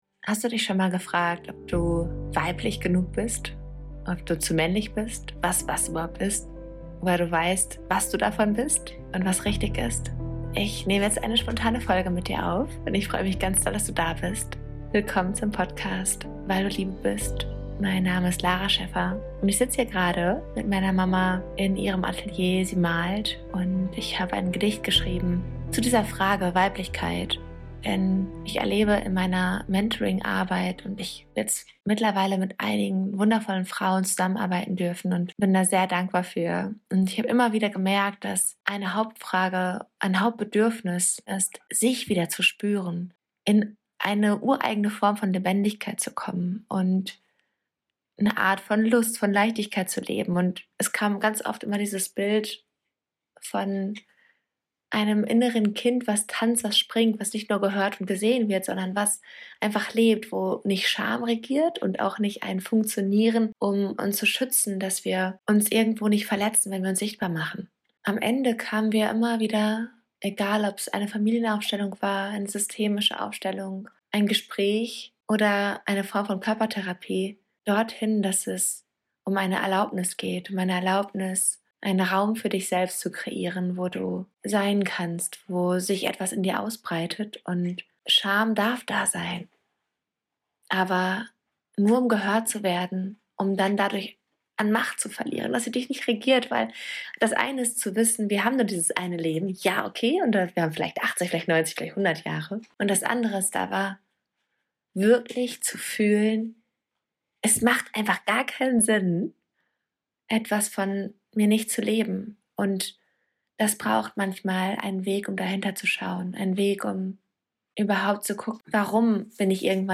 In dieser kurzen, poetischen Podcastfolge lese ich dir mein